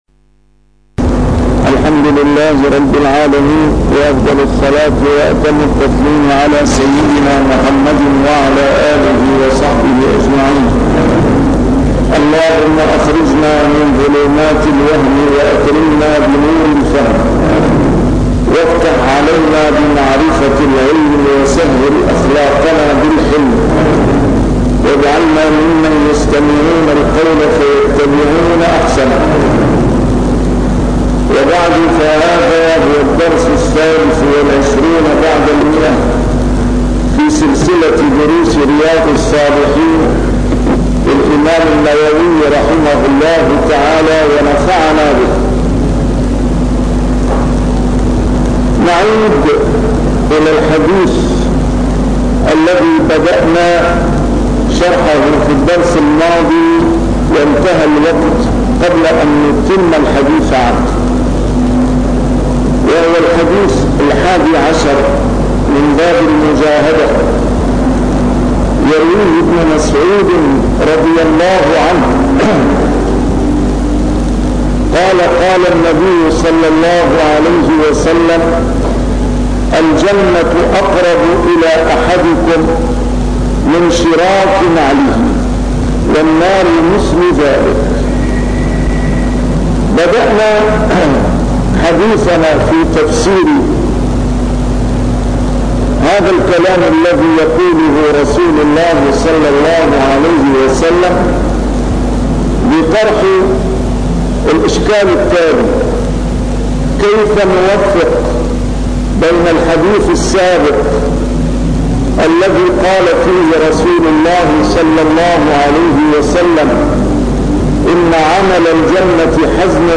A MARTYR SCHOLAR: IMAM MUHAMMAD SAEED RAMADAN AL-BOUTI - الدروس العلمية - شرح كتاب رياض الصالحين - 123- شرح رياض الصالحين: المجاهدة